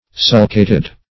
Search Result for " sulcated" : The Collaborative International Dictionary of English v.0.48: Sulcate \Sul"cate\, Sulcated \Sul"ca*ted\, a. [L. sulcatus, p. p. of sulcare to furrow, fr. sulcus a furrow.]
sulcated.mp3